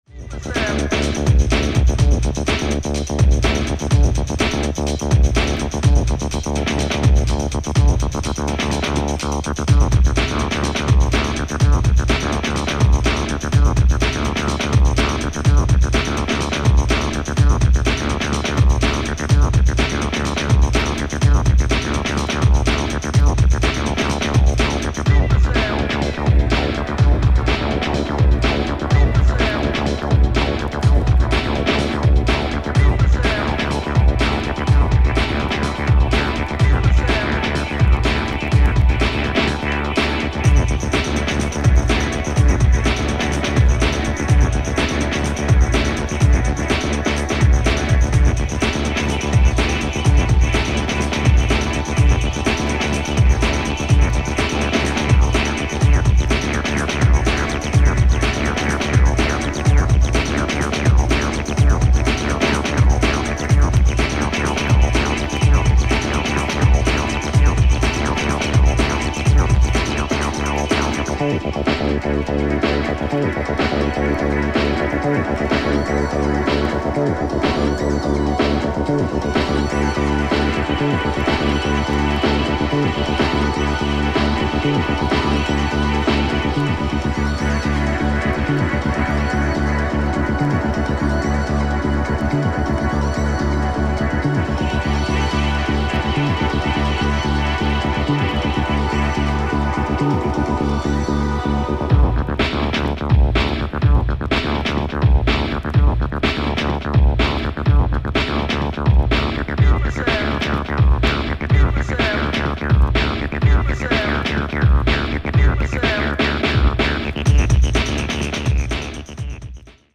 House Acid